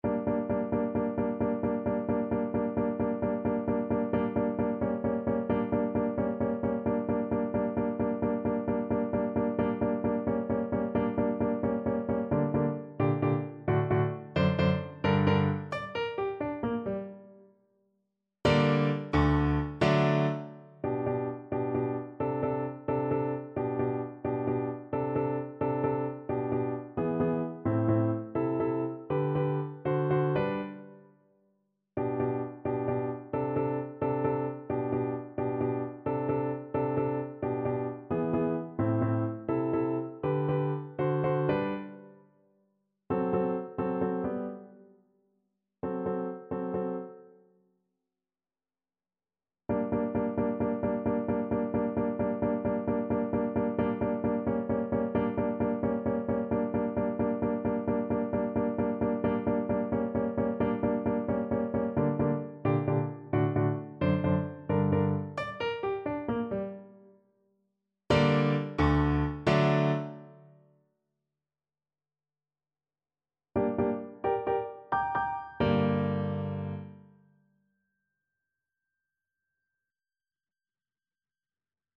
Flute
G minor (Sounding Pitch) (View more G minor Music for Flute )
Allegro con brio (.=104) .=88 (View more music marked Allegro)
Classical (View more Classical Flute Music)